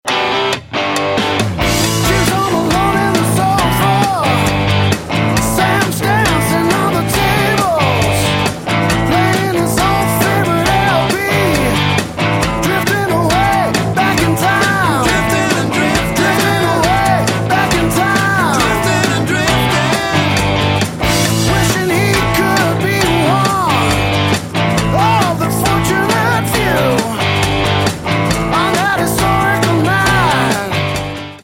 rockopera